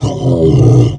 MONSTER_Pain_03_mono.wav